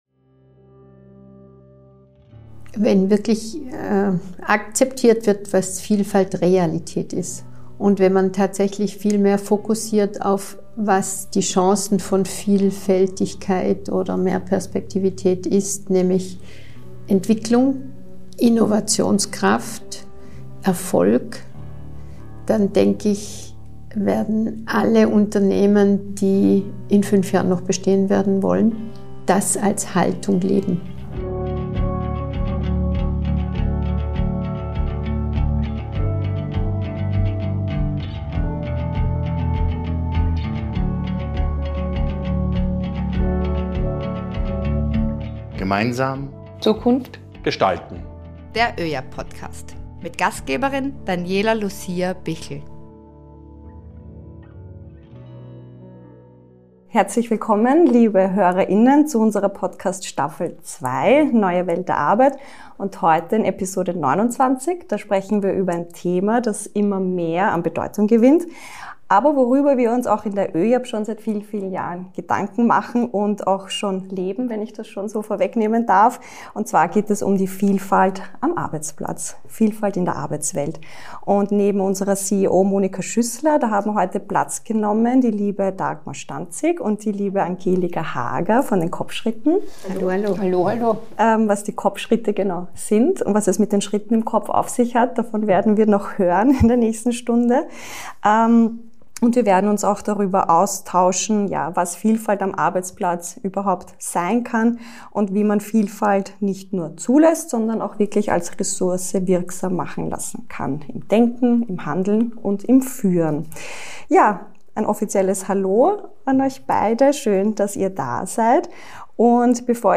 Im aktuellen Gespräch